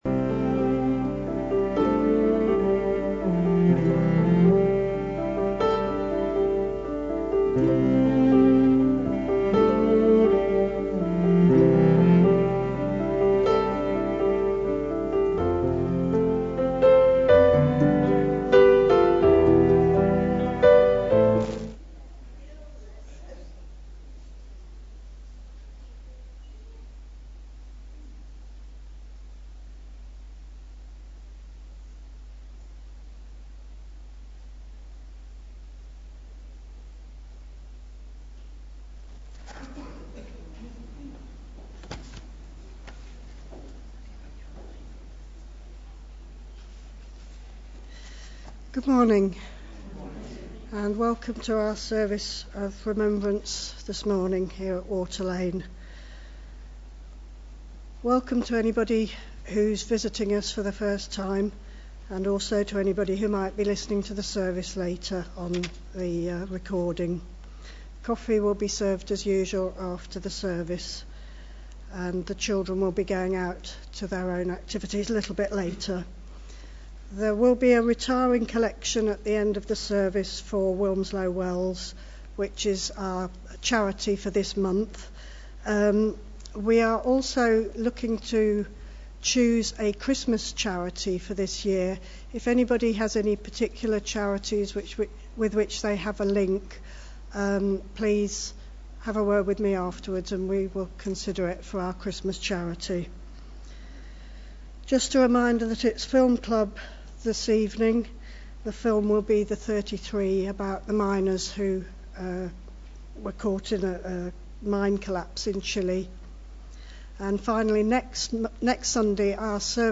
2019-11-10 Morning Worship
Genre: Speech.